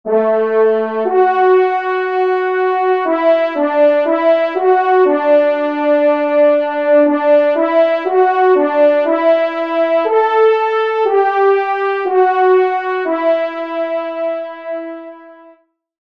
Genre : Fantaisie Liturgique pour quatre trompes
Pupitre 1° Trompe